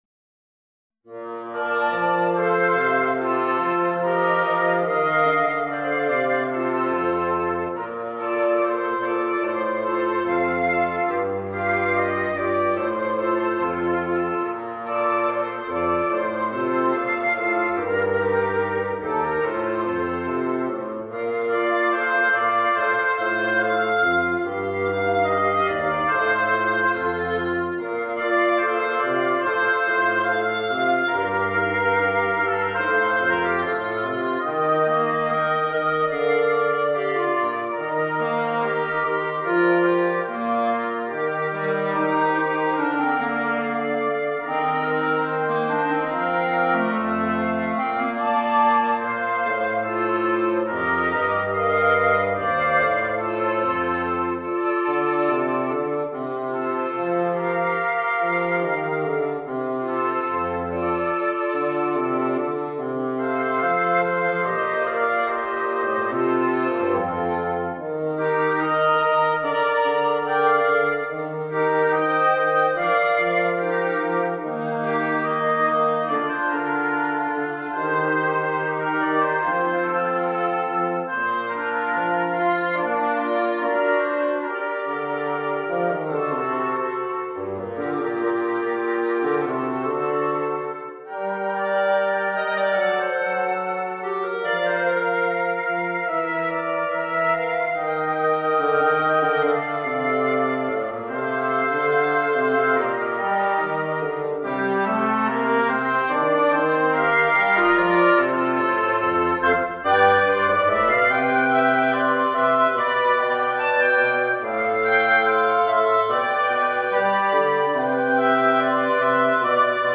(for Woodwind Quartet)
easy arrangement